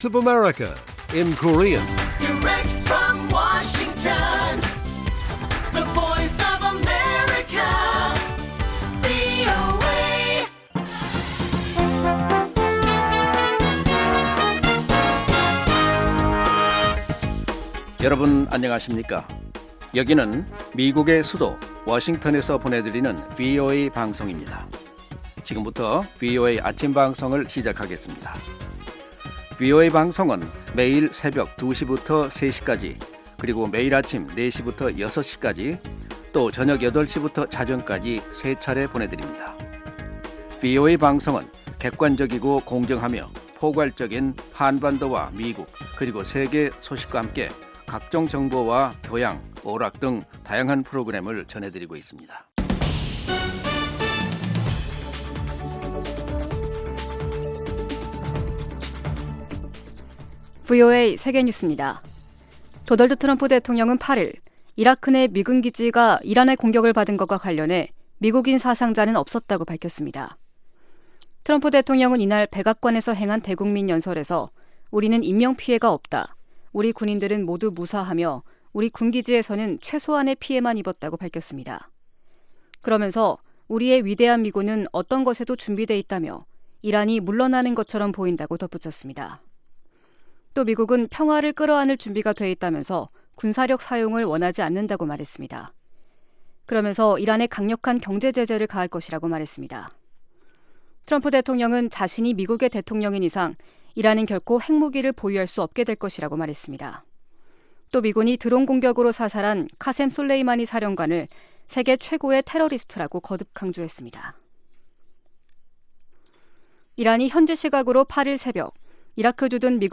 생방송 여기는 워싱턴입니다 2020 /1/9 아침